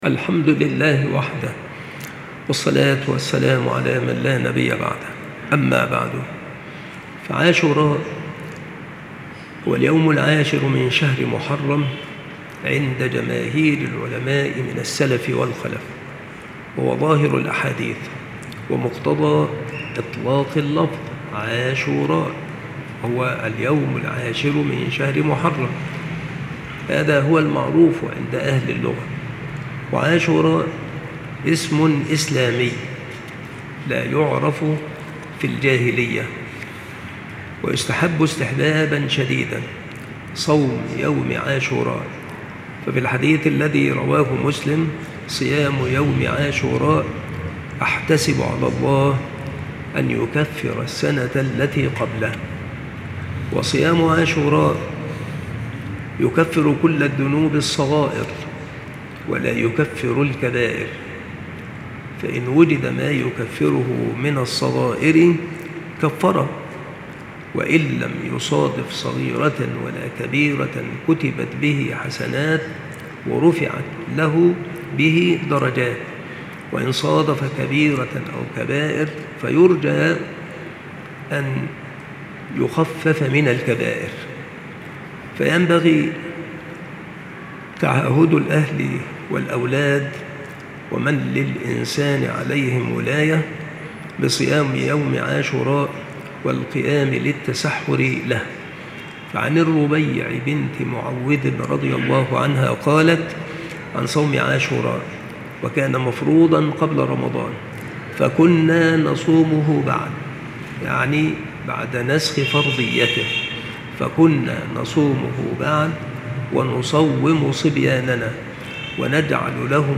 • مكان إلقاء هذه المحاضرة : بالمسجد الشرقي - سبك الأحد - أشمون - محافظة المنوفية - مصر